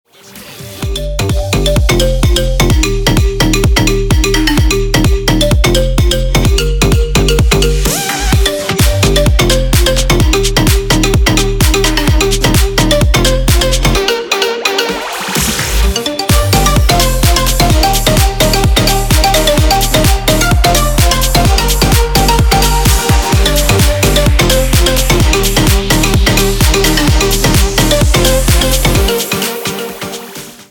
• Качество: 320, Stereo
Electronic
без слов
club
electro house
Melodic